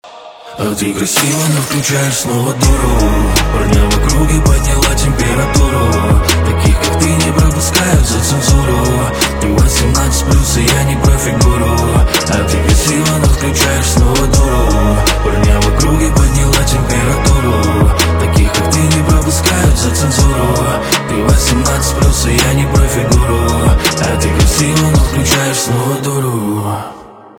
поп
битовые , басы